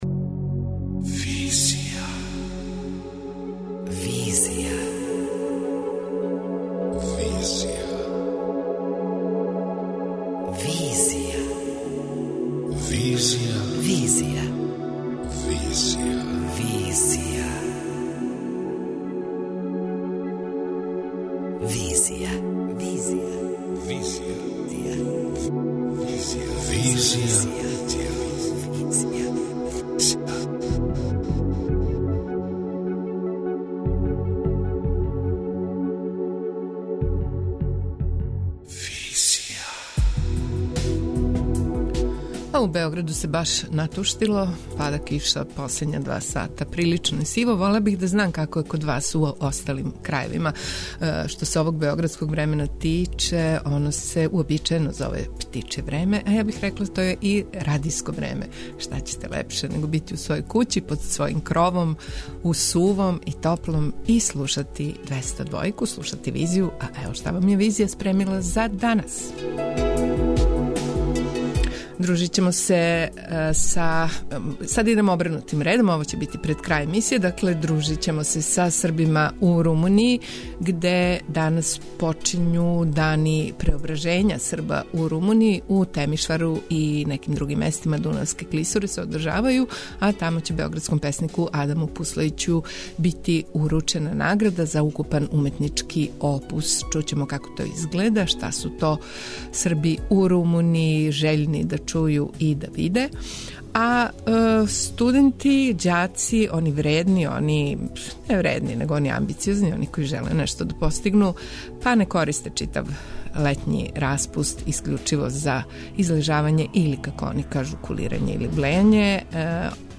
преузми : 27.85 MB Визија Autor: Београд 202 Социо-културолошки магазин, који прати савремене друштвене феномене.